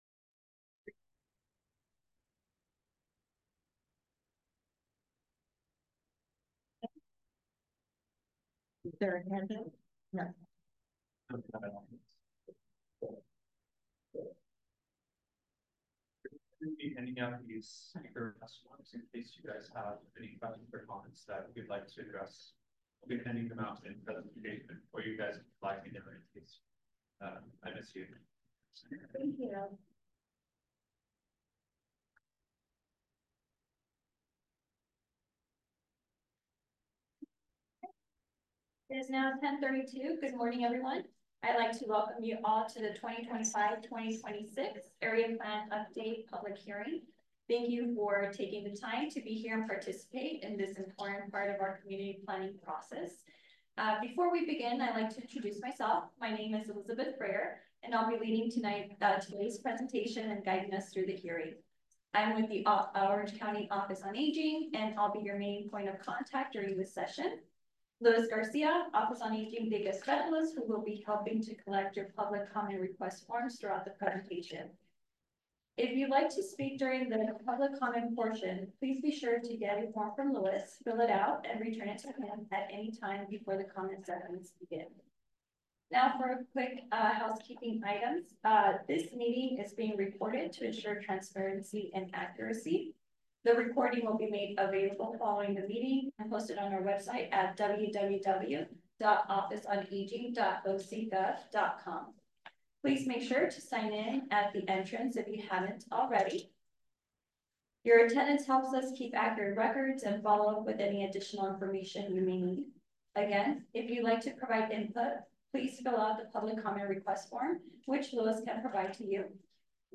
Public Hearing 4.24.25.mp3